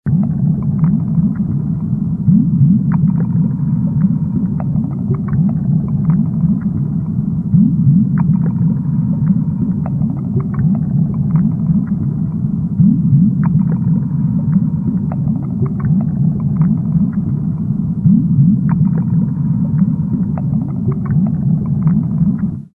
Звуки подводного мира и голоса акул